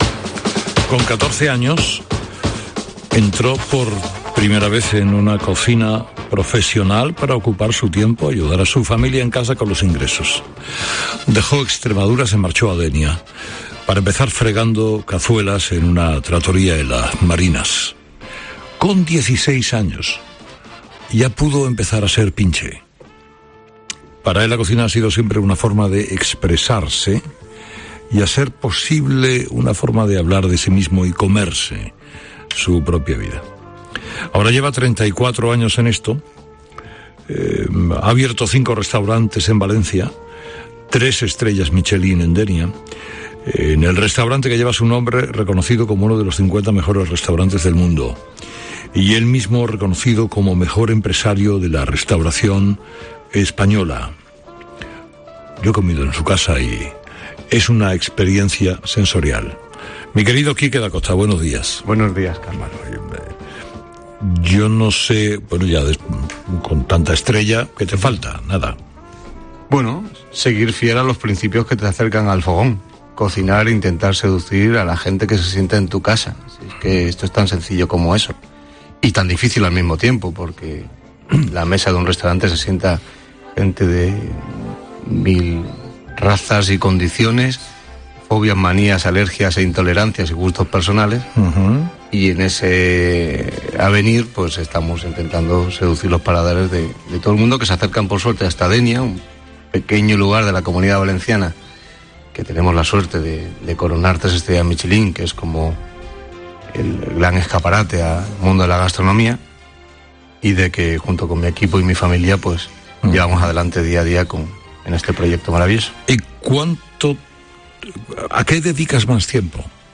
Carlos Herrera entrevista al chef extremeño Quique Dacosta